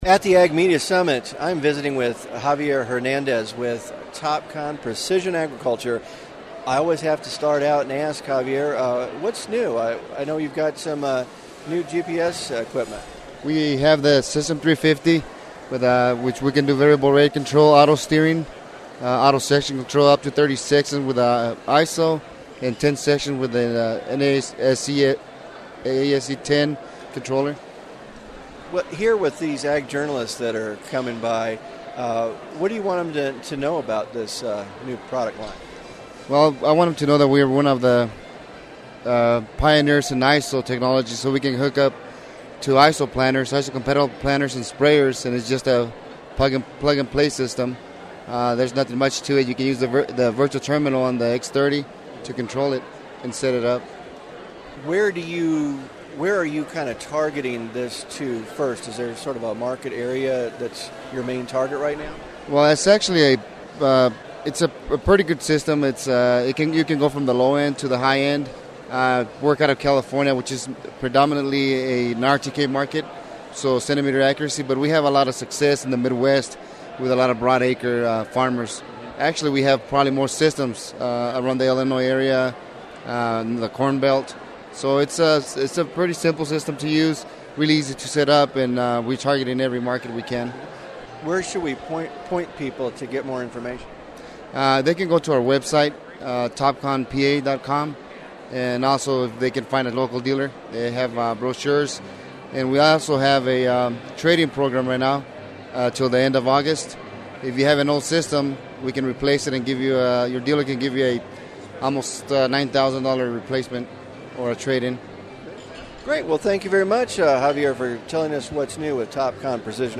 TopCon was one of the exhibitors at the Ag Media Summit.
Interview